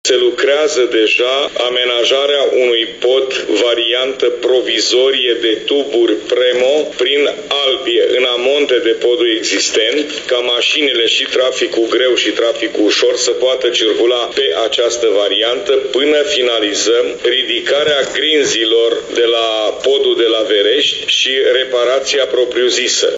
FLUTUR a declarat că la prima licitație nu s-a prezentat nicio firmă, așa încât procedura va fi reluată.